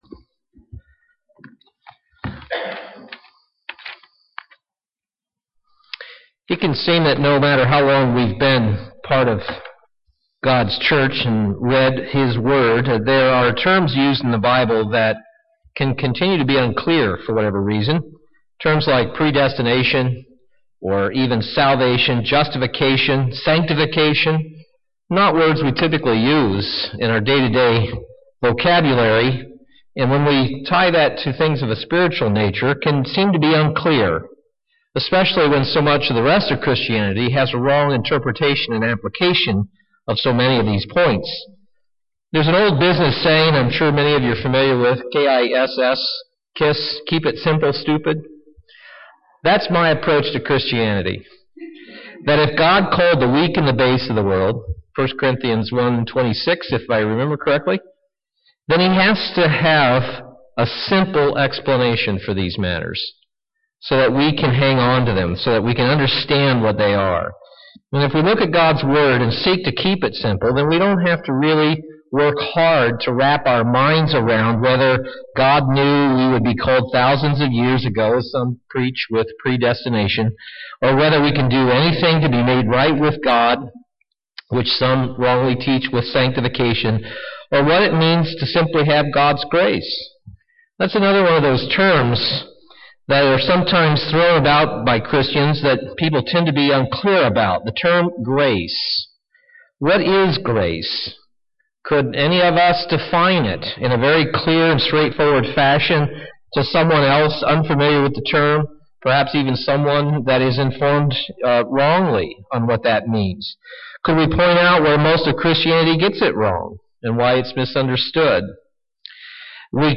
Sermon: Grow In Grace What is grace and how are we to grow in it?